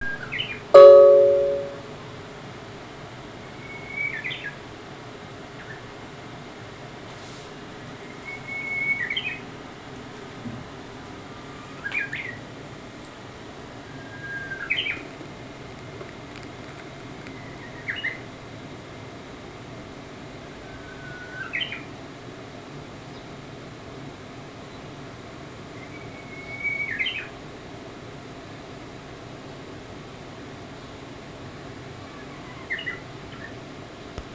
春の鳴き声・・・
隣の庭から、春を告げる、美しい鳴き声「
ウグイスの声.wav